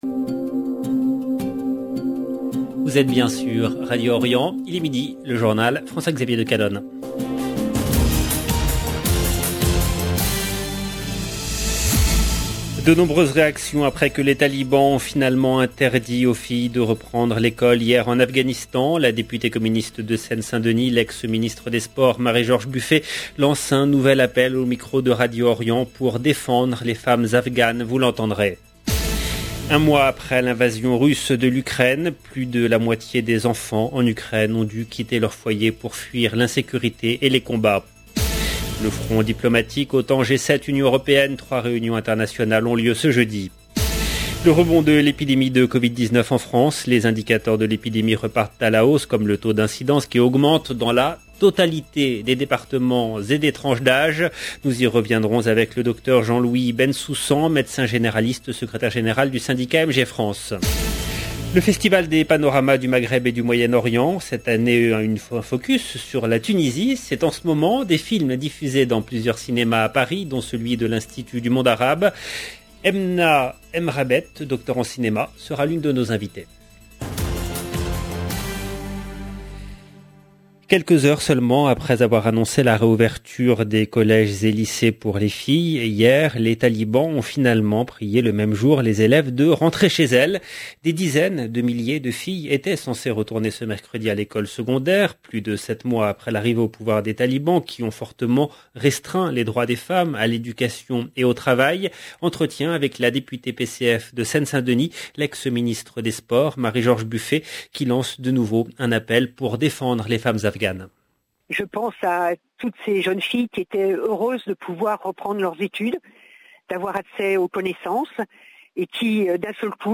La député PCF de Seine-Saint-Denis, l’ex ministre des sports Marie-George Buffet lance de nouveau un appel au micro de Radio Orient pour défendre les femmes afghanes. Vous l'entendrez. 1 mois après l’invasion russe de l’Ukraine, plus de la moitié des enfants en Ukraine ont dû quitter leur foyer pour fuir l'insécurité et les combats.